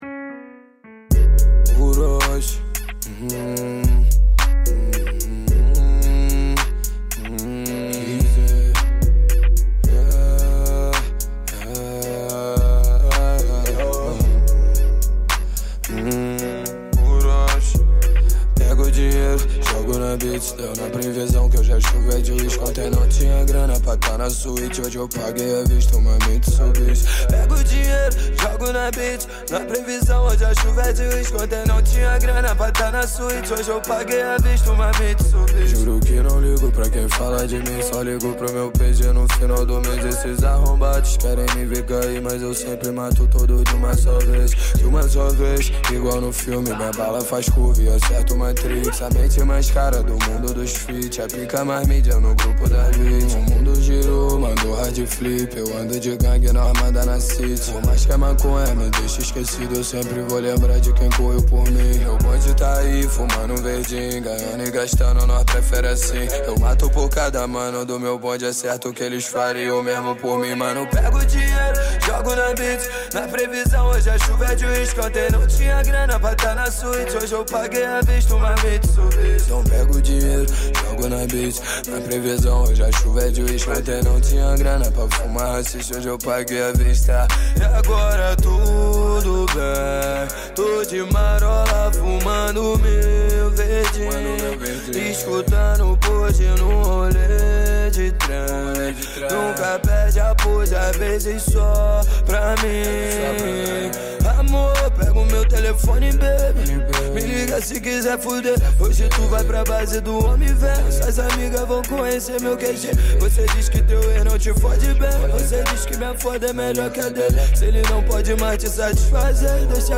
2024-04-10 13:29:26 Gênero: Trap Views